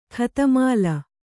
♪ khatamāla